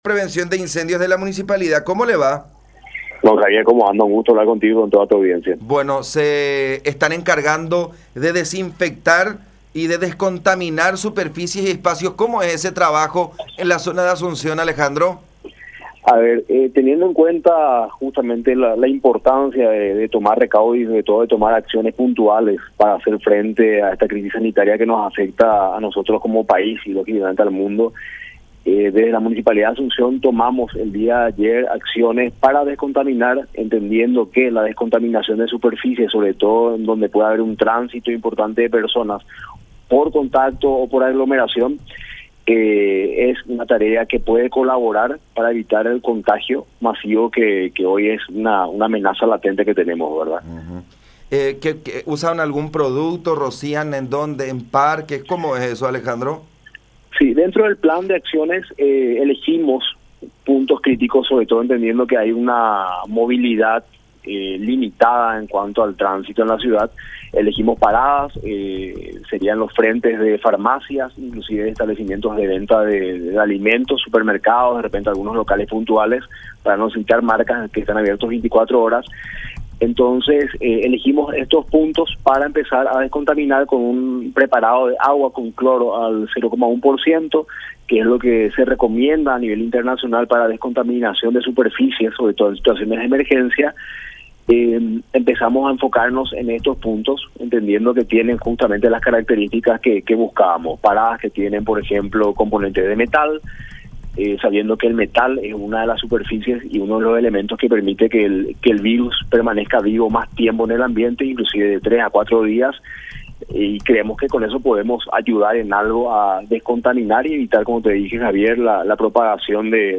“Teniendo en cuenta la importancia de tomar recaudos con esta epidemia, ayer comenzamos con tareas de descontaminación y desinfección de espacios y superficies. Dentro del plan de acciones elegimos puntos críticos donde se puede tener cierta cantidad de personas”, comentó Buzó en conversación con La Unión, citando como ejemplos a mercados, comercios concurridos, supermercados, farmacias, estacionamientos, paradas de buses y otros sitios que generen aglomeración de personas.